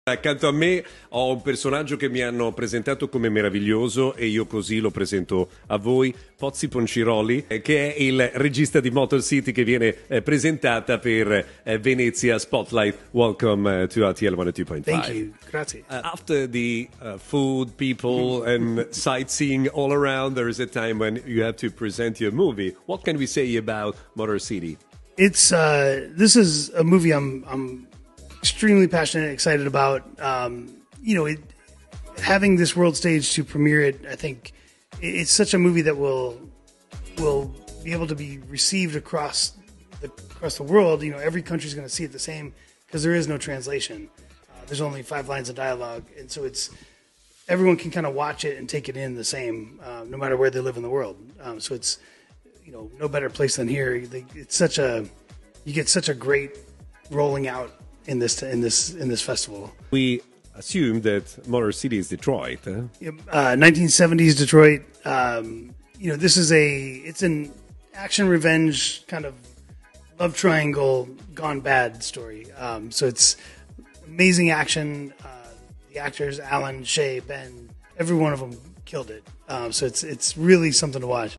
in diretta su RTL 102.5, “Motor City”, in concorso alla Mostra del Cinema di Venezia 2025, nella sezione “Venezia Spotlight”